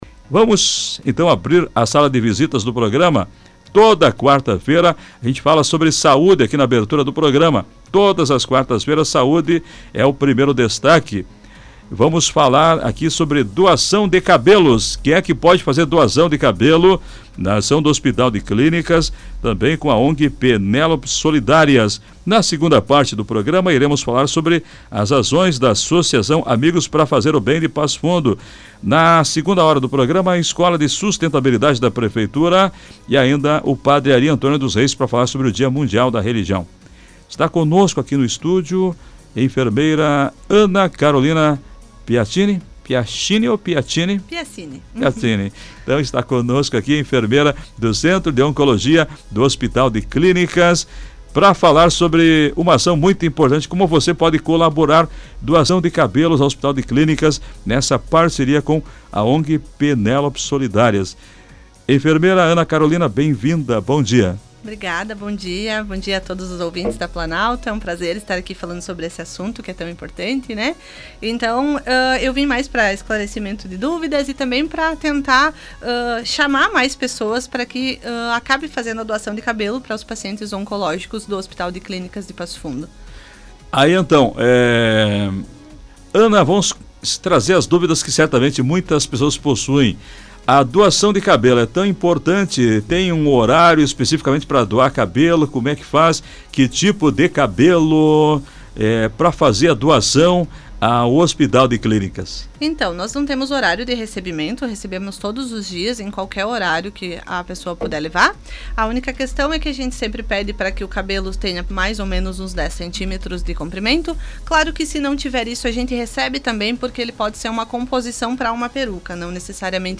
Entrevista: a doação de cabelos como um gesto de amor integra HC e Penélopes Solidárias
O programa Comando Popular, da Rádio Planalto News (92.1), colocou em destaque no quadro de Saúde dessa quarta-feira, 21, a importância da doação de cabelos para o Centro de Oncologia do Hospital de Clínicas (HC) em Passo Fundo.